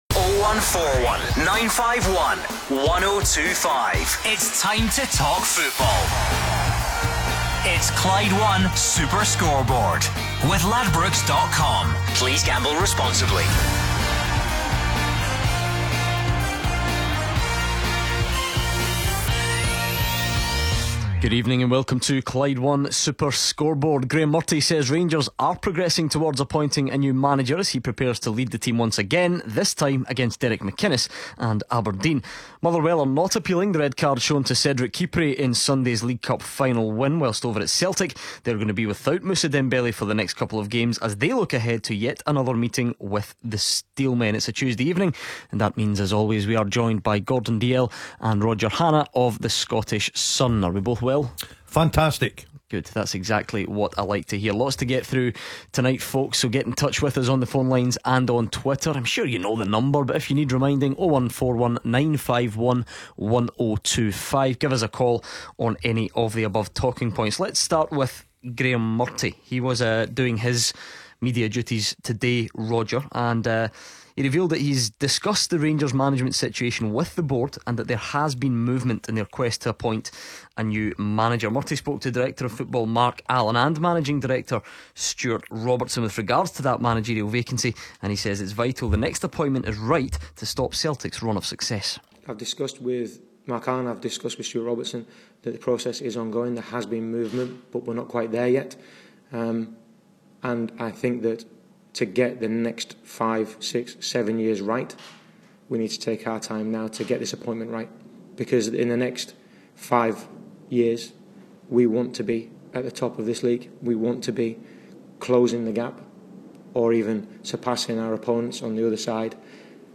take your calls...